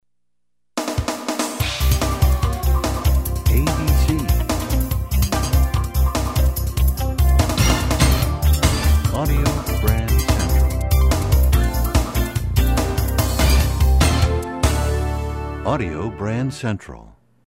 MCM Category: Radio Jingles